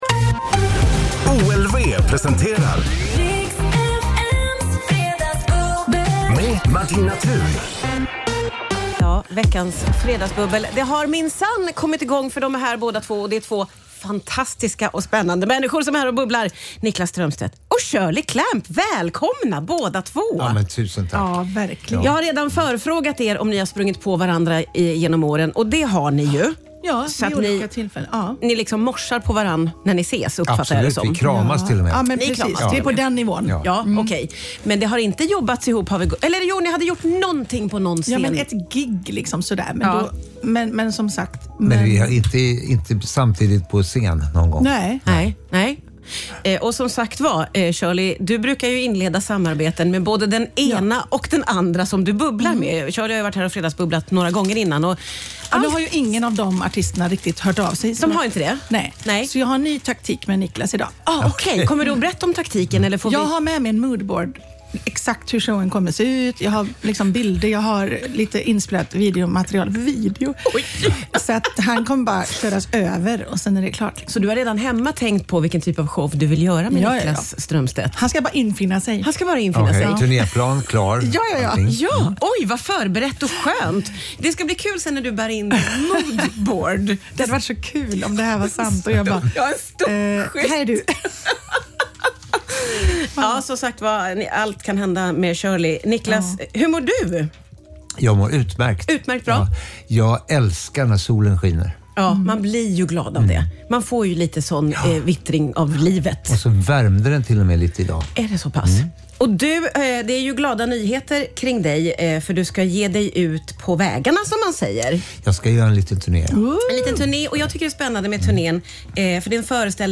En helt fantastisk start på helgen (med mycket skratt såklart)